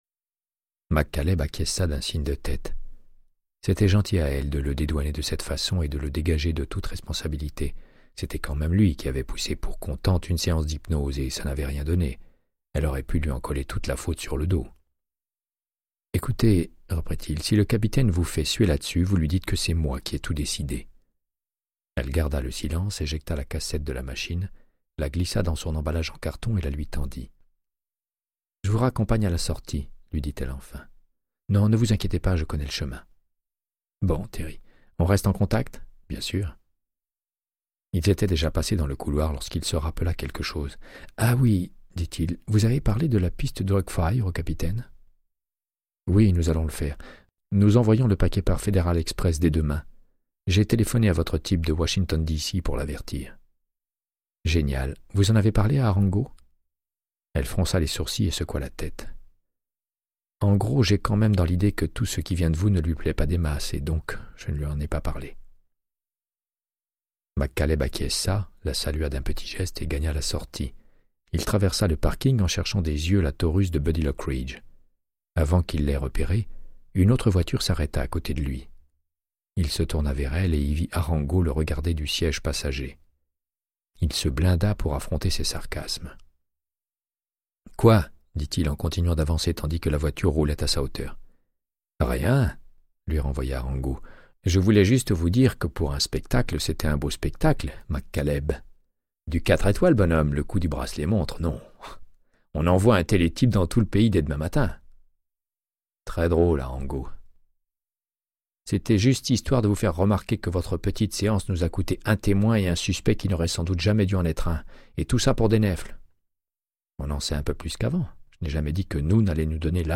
Audiobook = Créance de sang, de Michael Connellly - 72